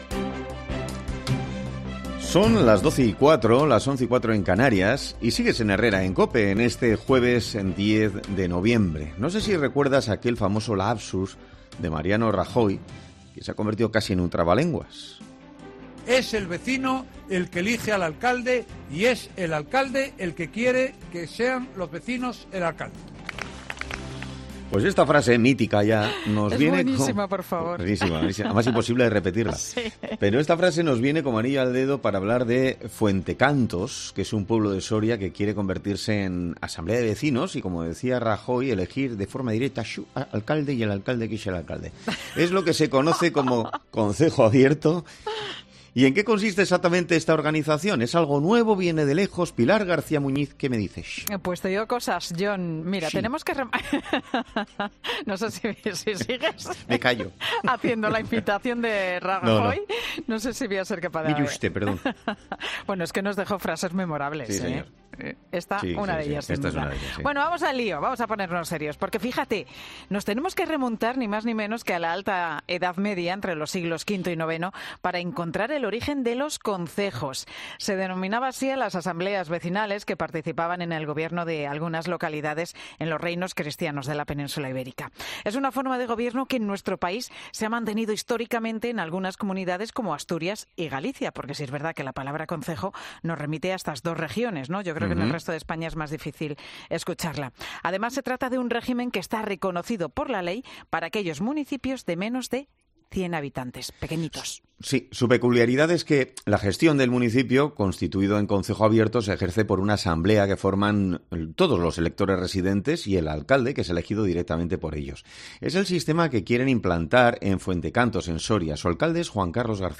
Entrevistamos a Ascensión Giménez Santolaria, alcaldesa de Villadoz, en Zaragoza